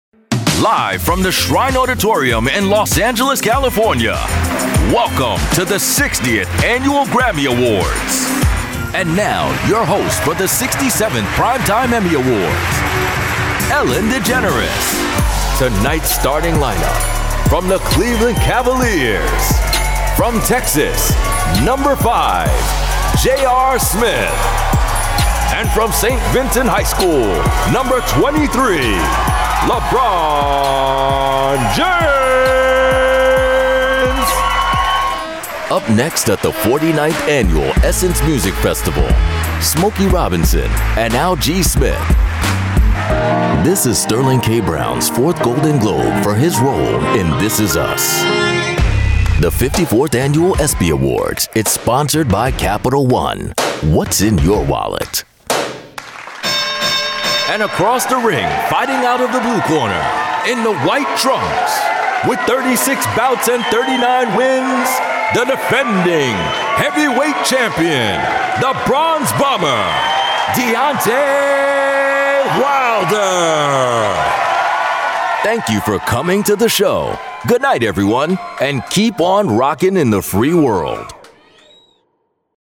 Profound, Resonant, Real.
Live Announce